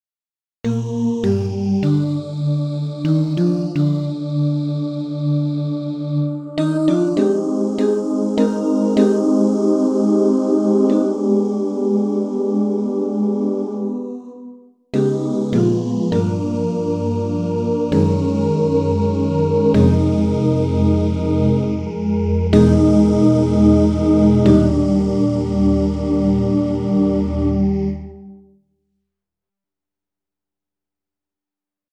Key written in: E♭ Major
How many parts: 5
All Parts mix: